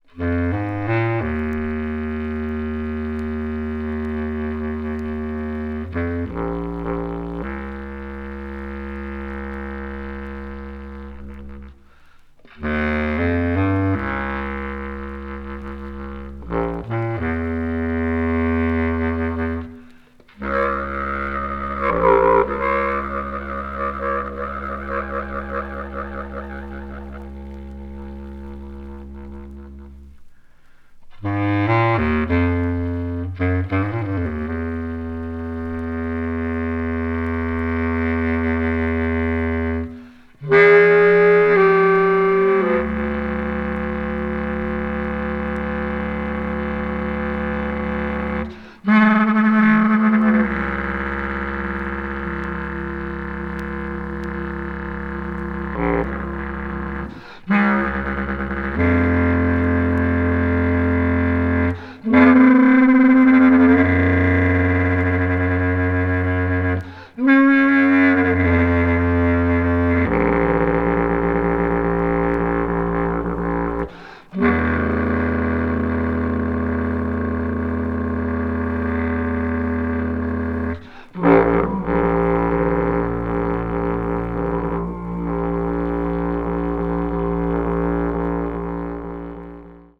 同年にドイツ・ベルリンで行われたソロ・コンサートのライヴ・レコーディング音源を収録。フルート、ヴィブラフォン、バス。